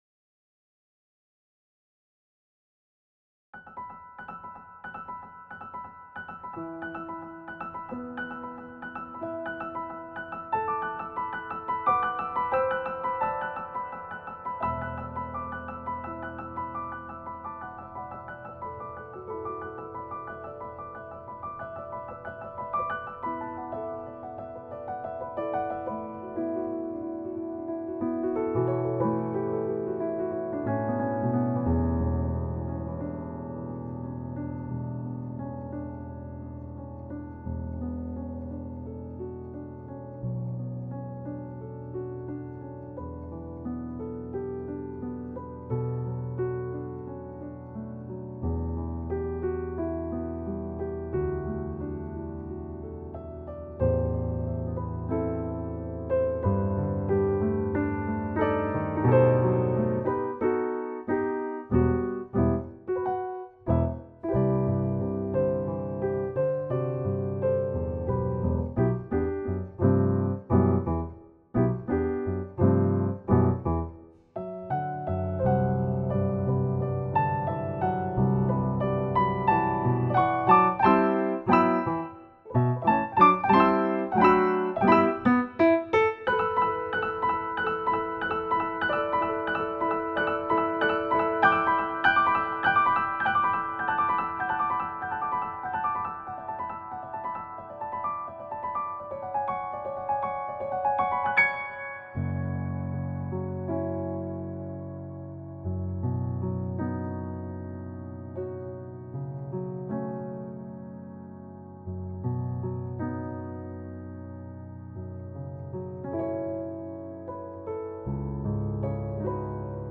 Rain on Princess - Piano Music, Solo Keyboard
I figured subdued dynamics and generous use of the sustain pedal were important to the sound I wanted.